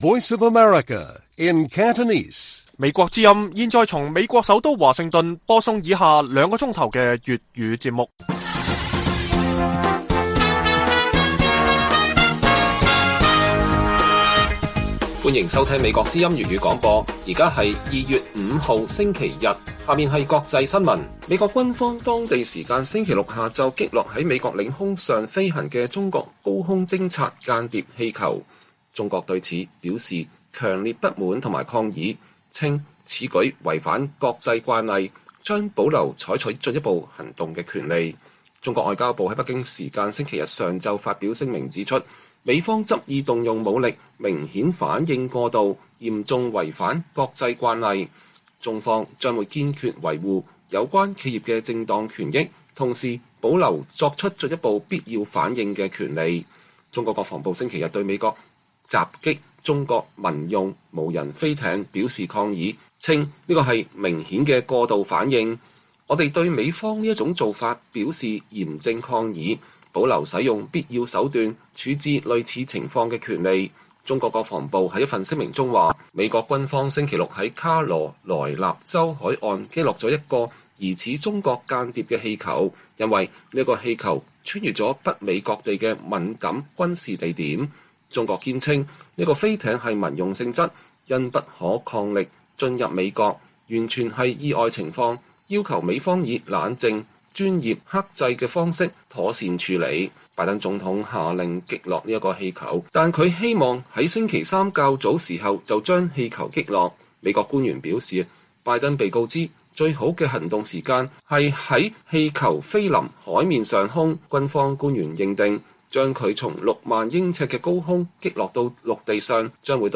粵語新聞 晚上9-10點 : 華為成為美國打擊中國科技的最新目標